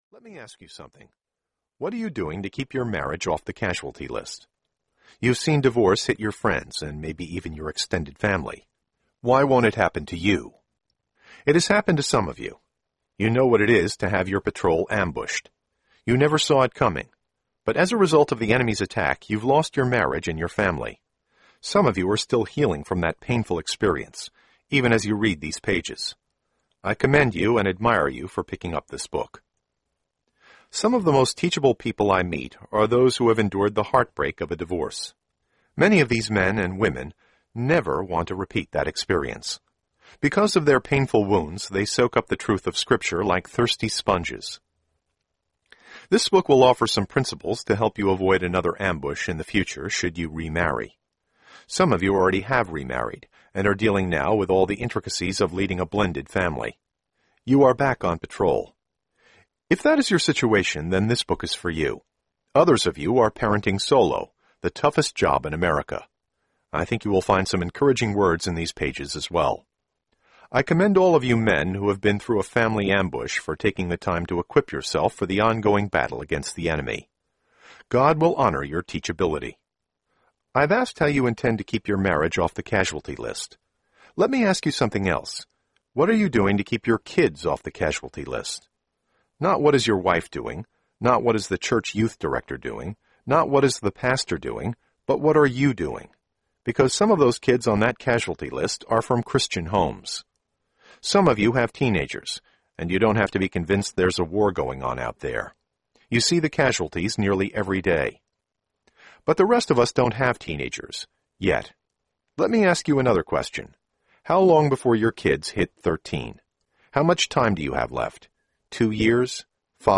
Point Man Audiobook
Narrator
9 Hrs. – Unabridged